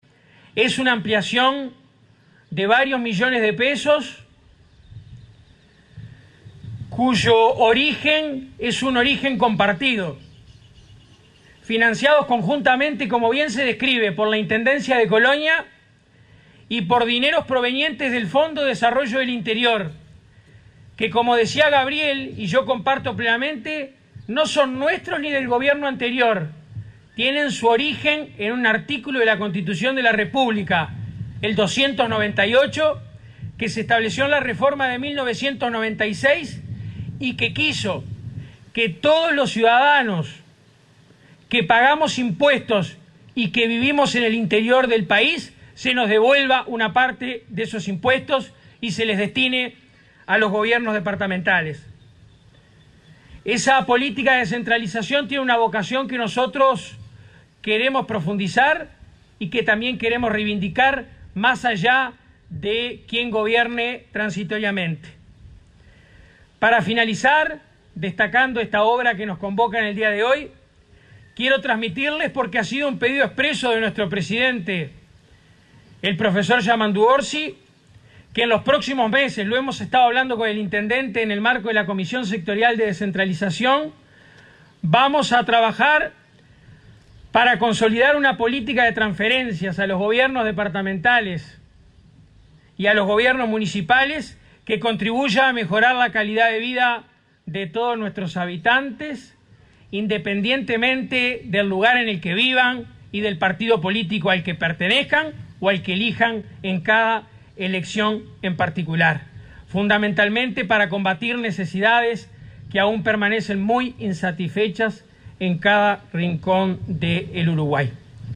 La Intendencia de Colonia y la OPP inauguraron este miércoles las obras de infraestructura e iluminación realizadas en el espacio público del Parque lineal AFE en Colonia del Sacramento.